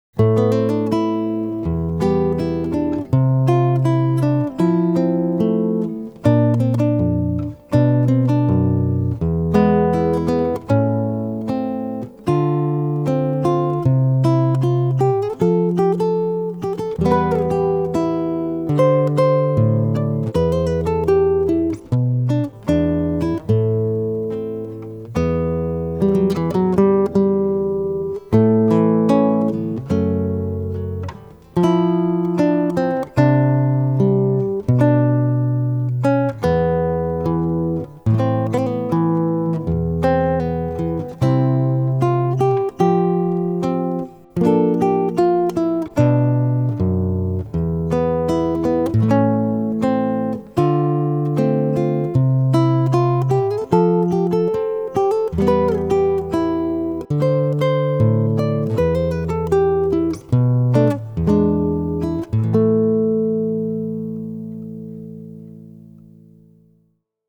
בעמוד זה תמצאו כ-80 עיבודי גיטרה ברמת ביניים